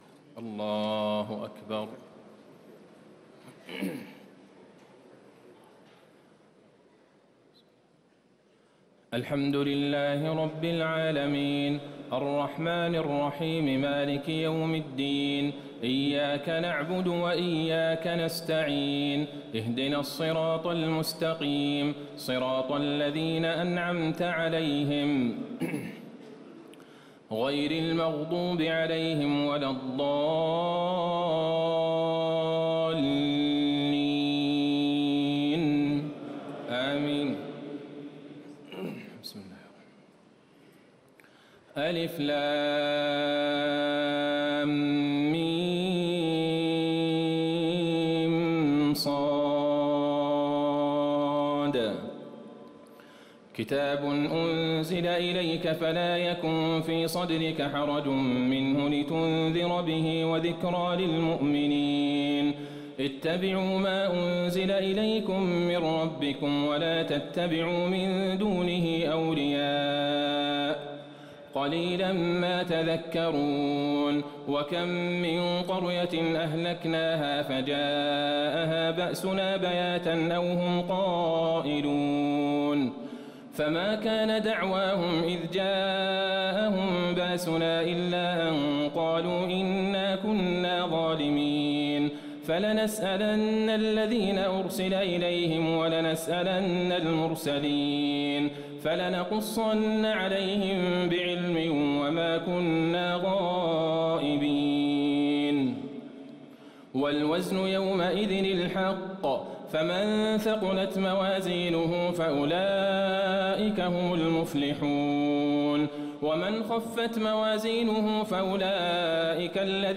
تهجد ليلة 28 رمضان 1438هـ من سورة الأعراف (1-84) Tahajjud 28 st night Ramadan 1438H from Surah Al-A’raf > تراويح الحرم النبوي عام 1438 🕌 > التراويح - تلاوات الحرمين